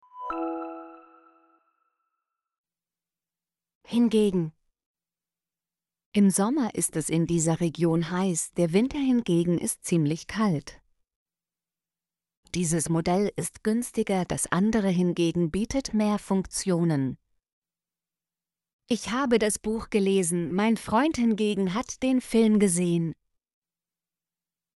hingegen - Example Sentences & Pronunciation, German Frequency List